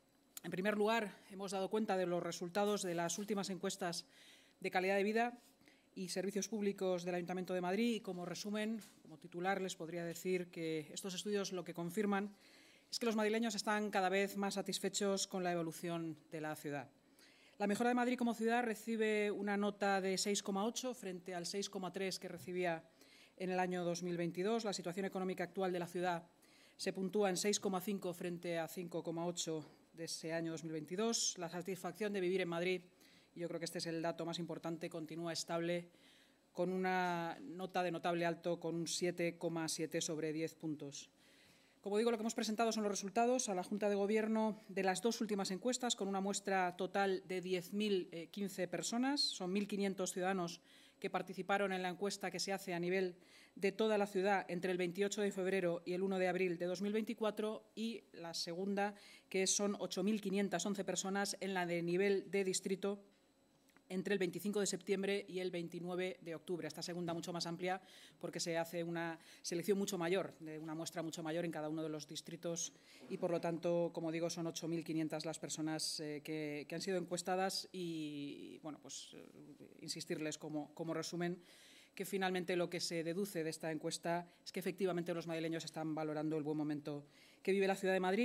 La vicealcaldesa y portavoz municipal, Inma Sanz, en la rueda de prensa posterior a la Junta de Gobierno